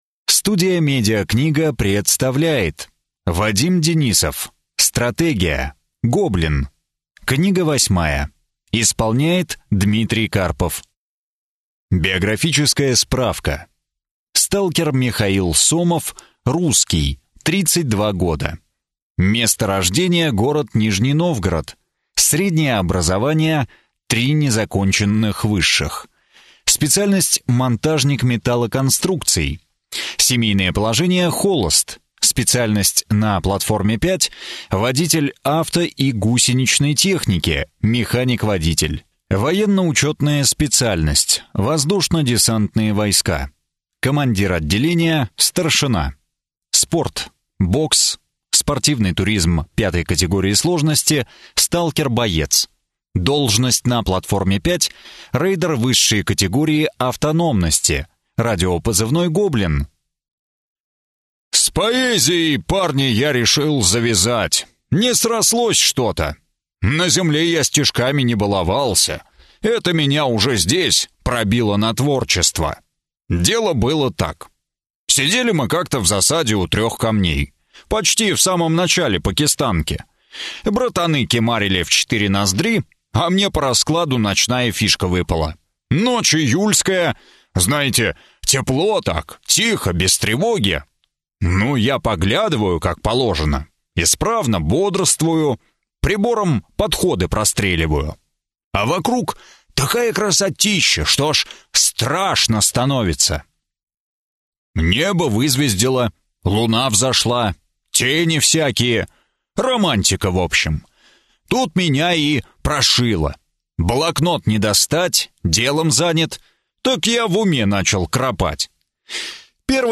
Аудиокнига Стратегия. Гоблин | Библиотека аудиокниг